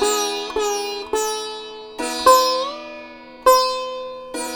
105-SITAR2-L.wav